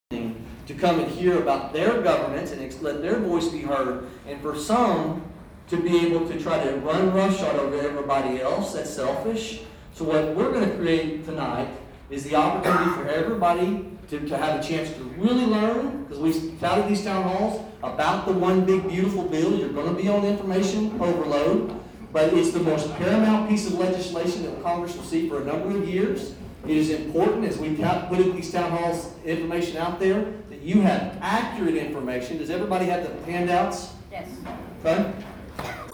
Prior to the presentation before a large crowd at Tri County Tech, Brecheen lead in the Pledge of Allegiance